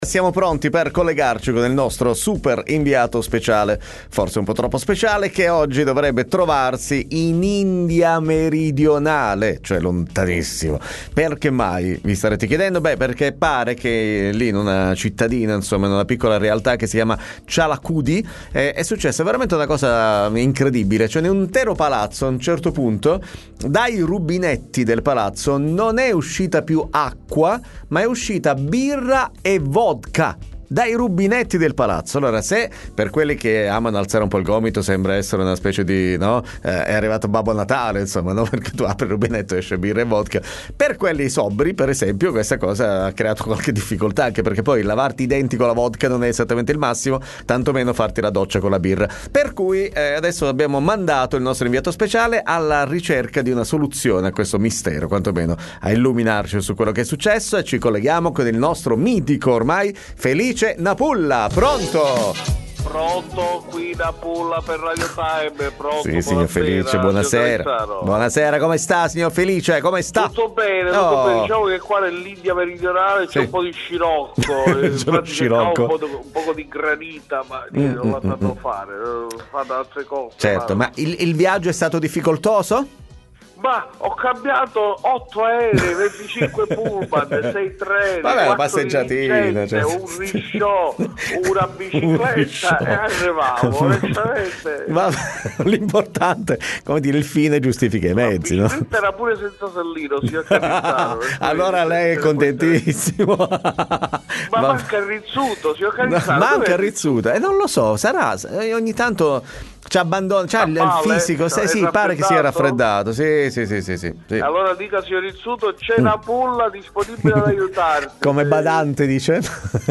Doc Time intervista